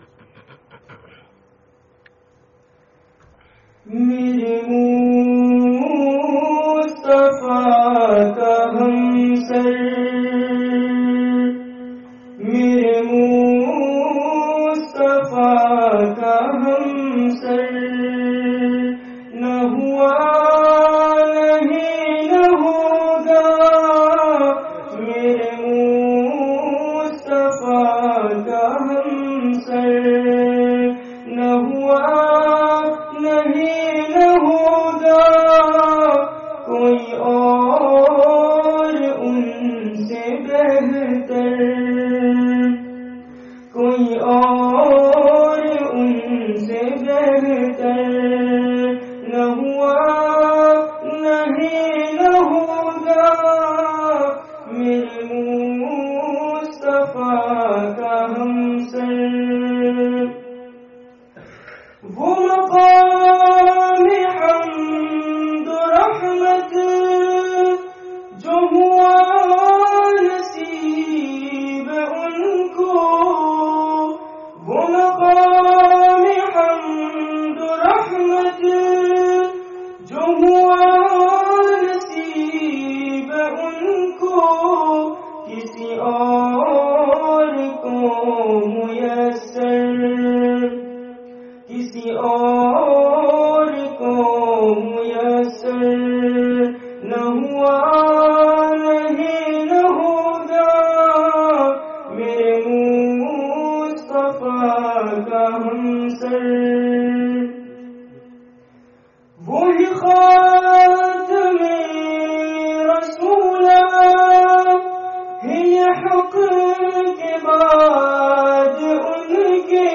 Qur'ān awr Nabī ﷺ: Do Barī Ni'mate(n) (6th Annual Seerah Conference, Nelson 30/11/19)